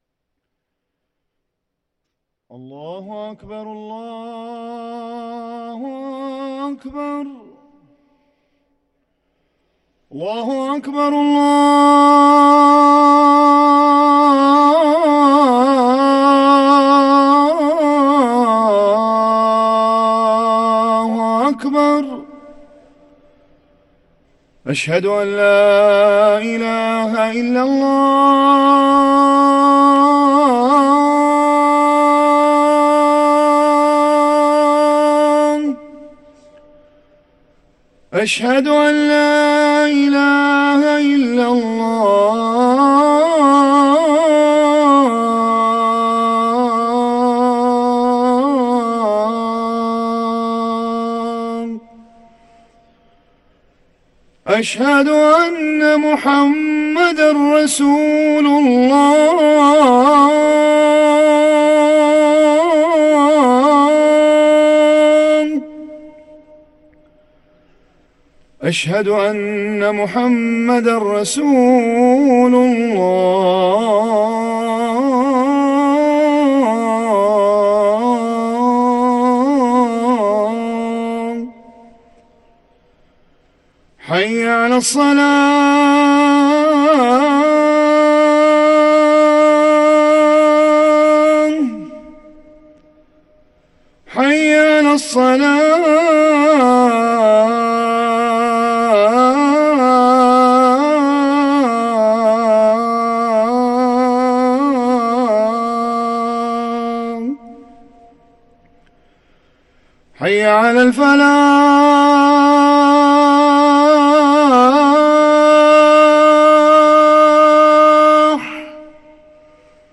ركن الأذان